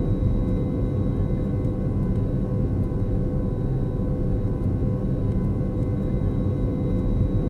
jetflight.ogg